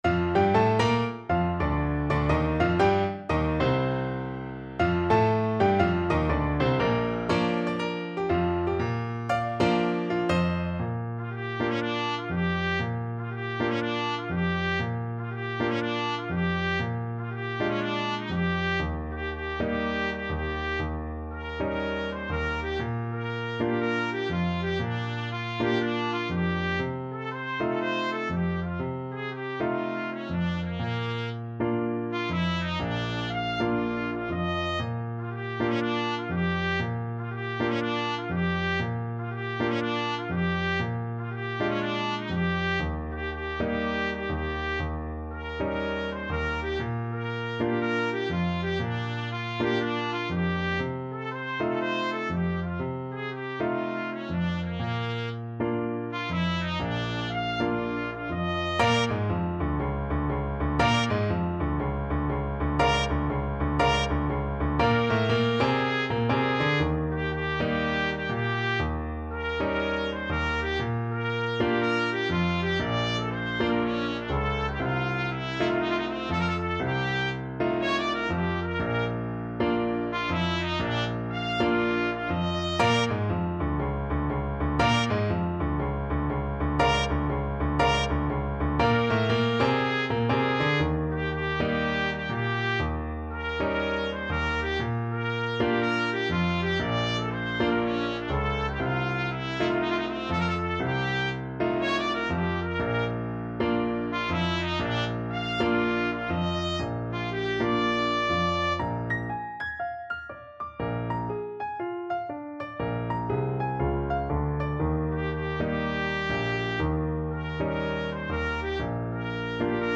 Moderato = 120
4/4 (View more 4/4 Music)
Jazz (View more Jazz Trumpet Music)